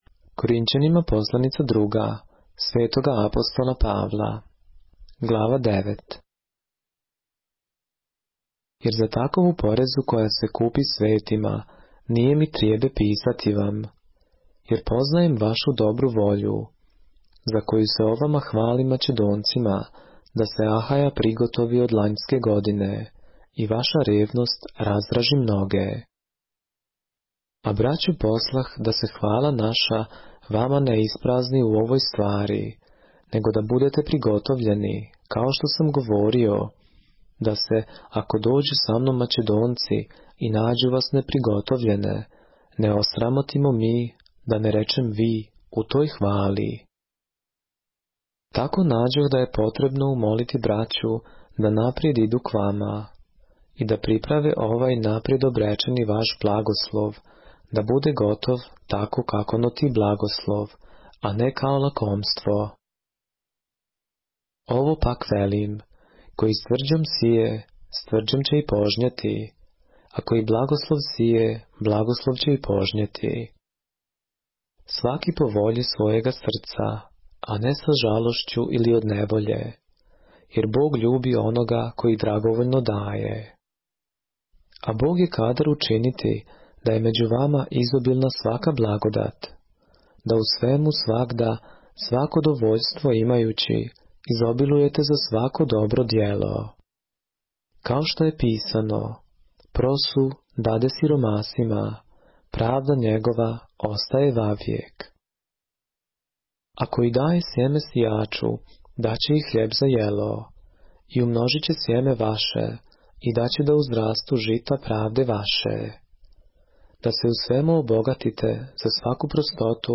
поглавље српске Библије - са аудио нарације - 2 Corinthians, chapter 9 of the Holy Bible in the Serbian language